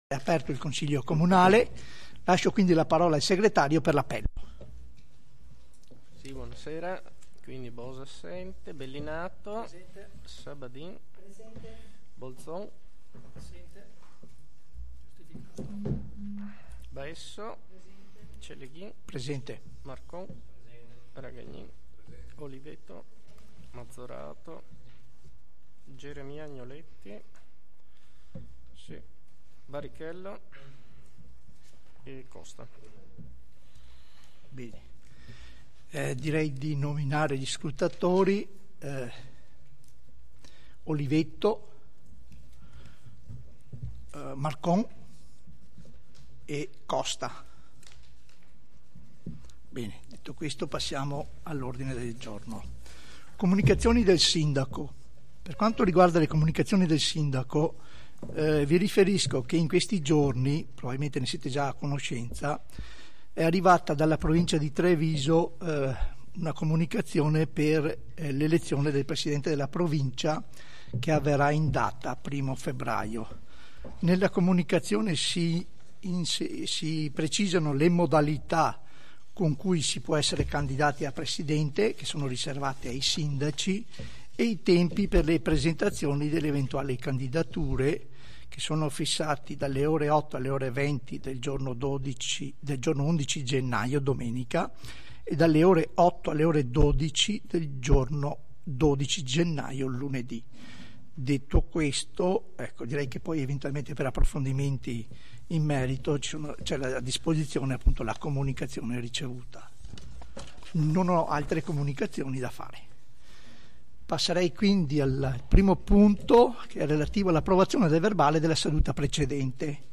Seduta di Consiglio Comunale del 30/12/2025